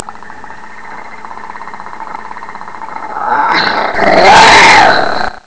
Snarl.wav